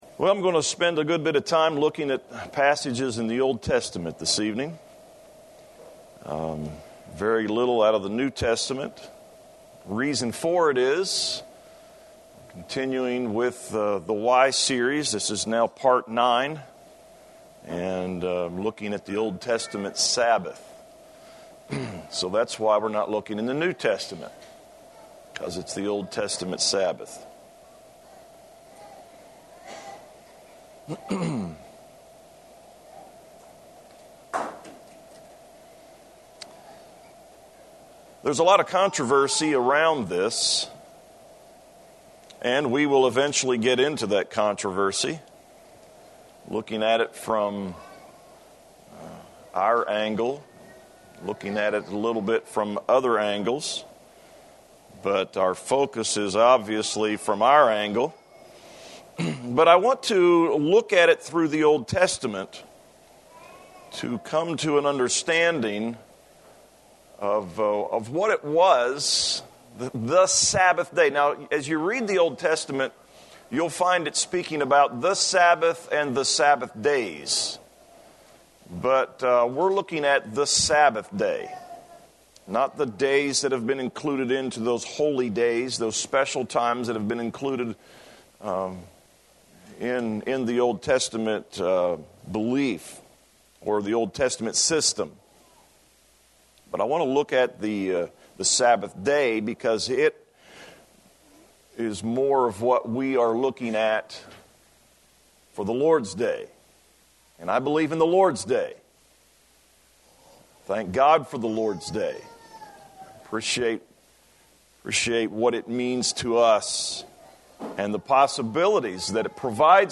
Save Audio This sermon